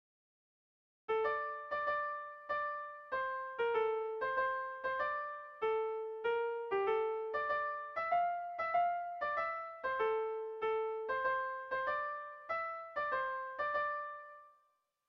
Gernika-Lumo < Busturialdea < Bizkaia < Euskal Herria
Kopla handia
ABD